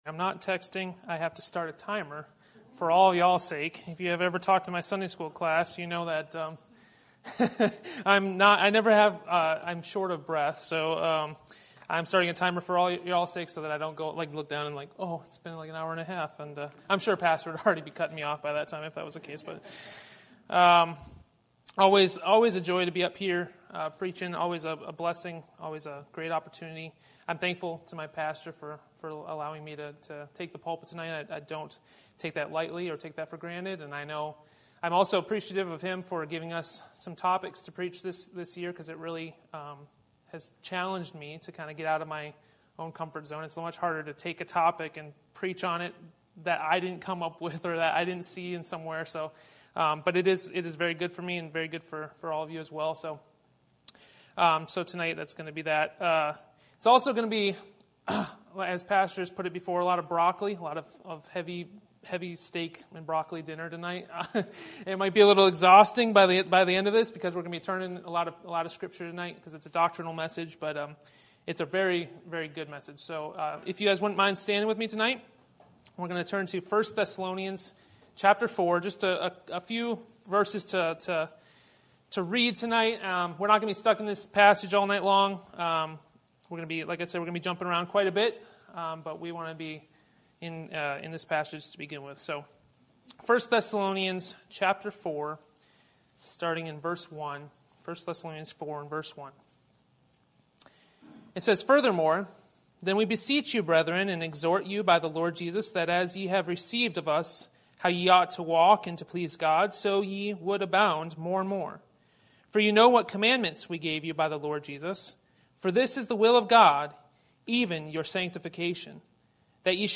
Sermon Topic: General Sermon Type: Service Sermon Audio: Sermon download: Download (25.99 MB) Sermon Tags: 1 Thessalonians Sanctification Holy Doctrine